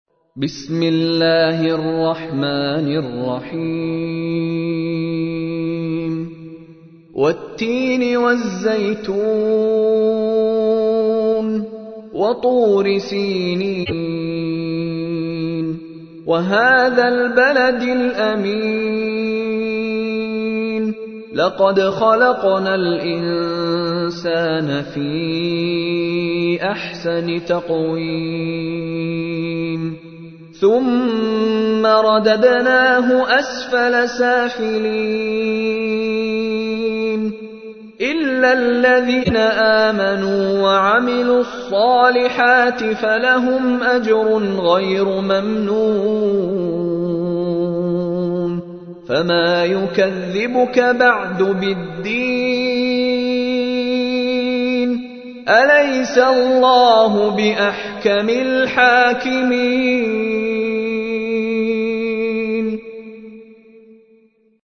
تحميل : 95. سورة التين / القارئ مشاري راشد العفاسي / القرآن الكريم / موقع يا حسين